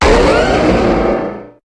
Cri de Méga-Carchacrok dans Pokémon HOME.
Cri_0445_Méga_HOME.ogg